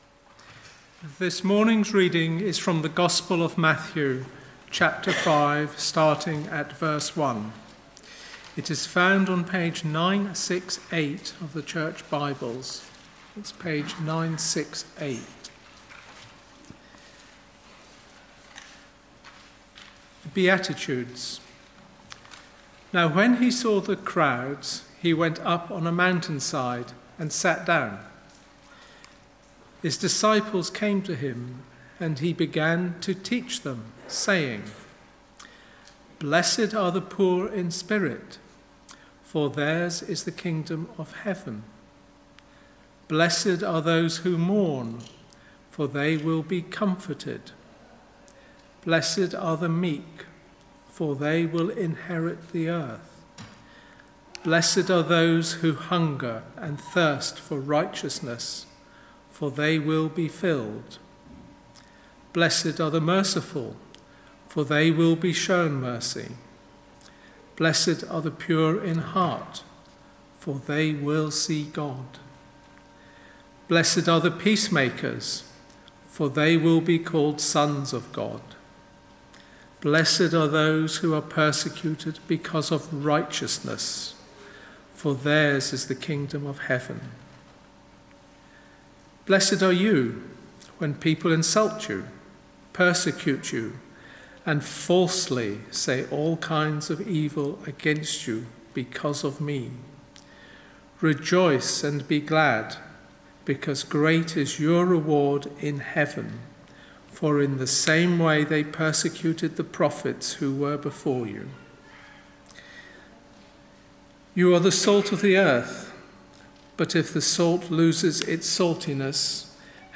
Sermon points - Matthew 5:1-16, Salt and Light
Service Type: Sunday Morning